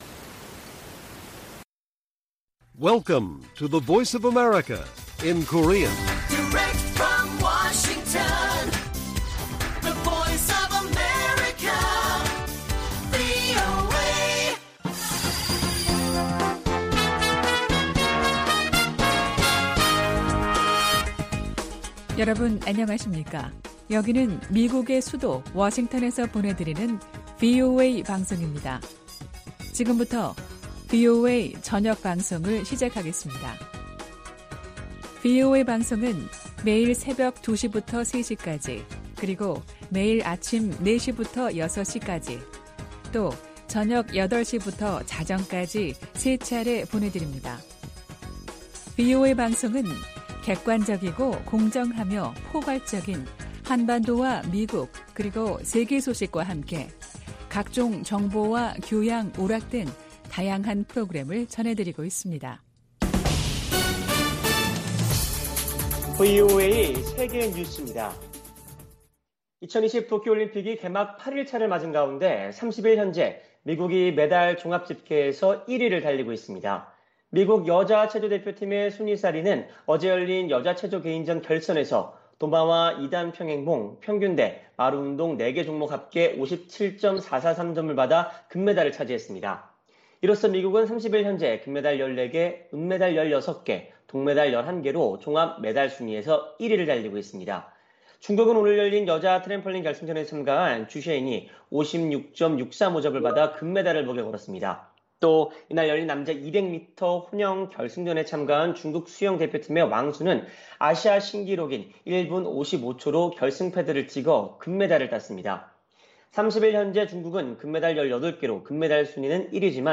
VOA 한국어 간판 뉴스 프로그램 '뉴스 투데이', 1부 방송입니다.